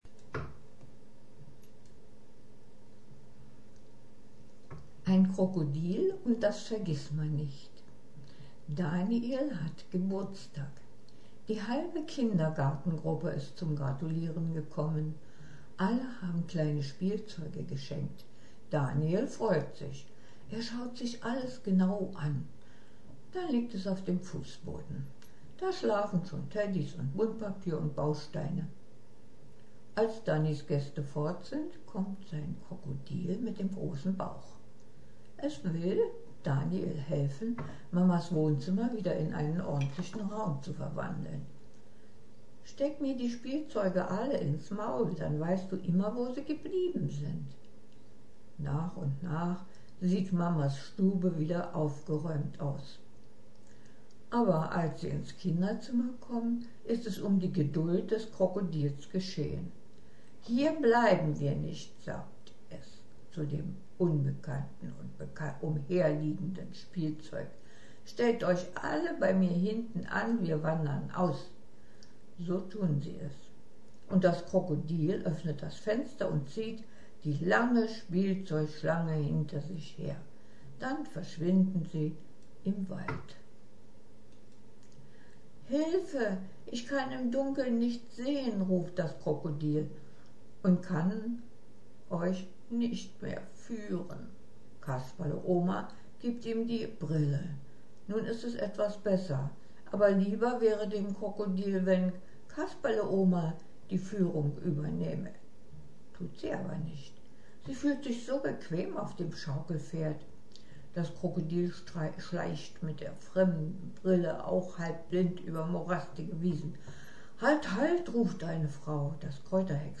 Text gesprochen: